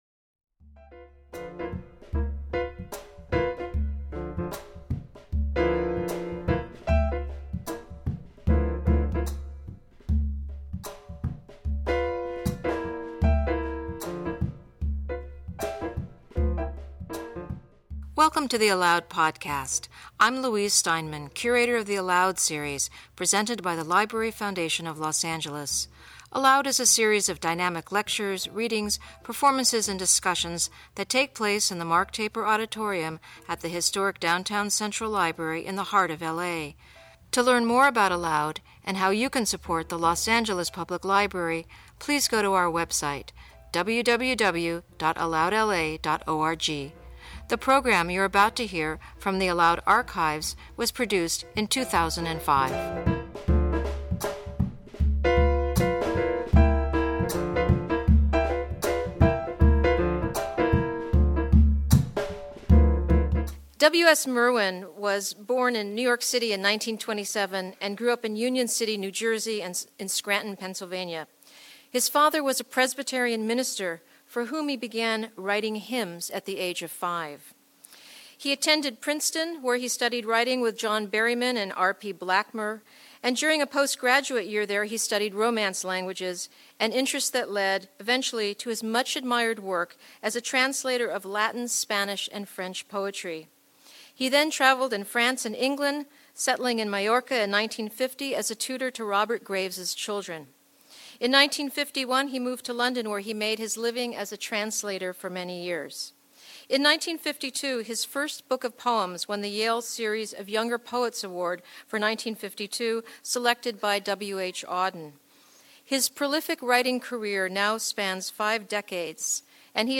An evening with poet W.S.Merwin